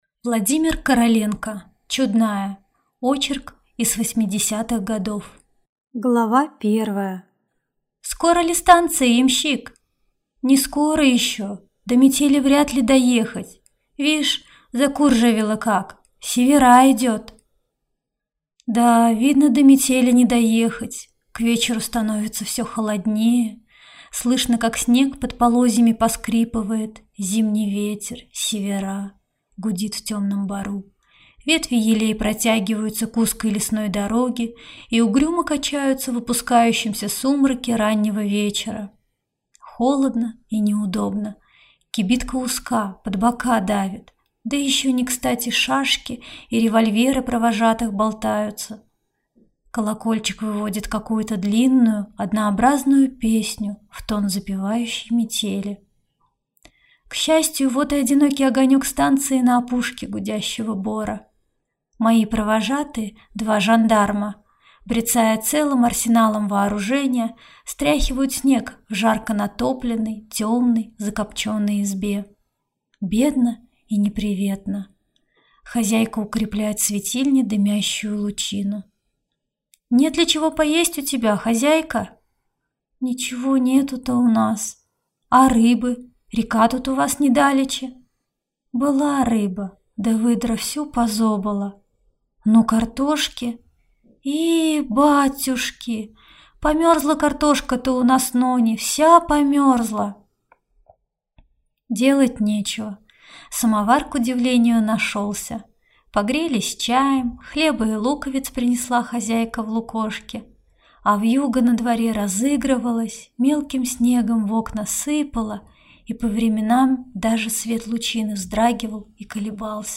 Аудиокнига Чудная | Библиотека аудиокниг